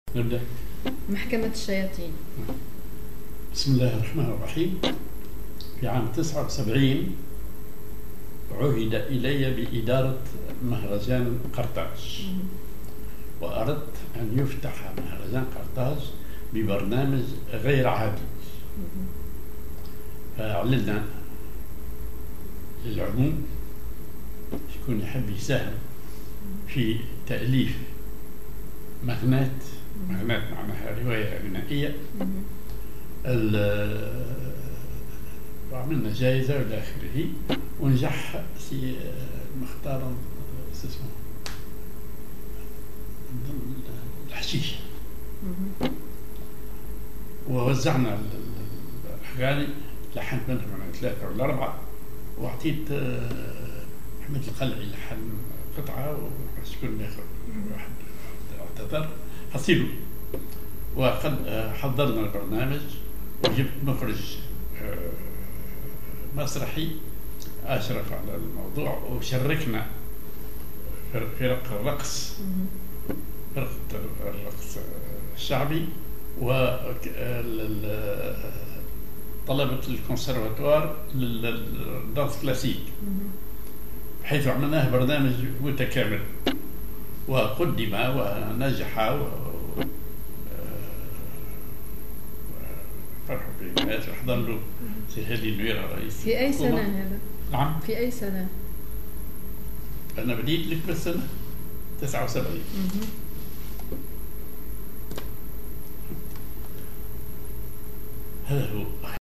genre أغنية
description ar مغناة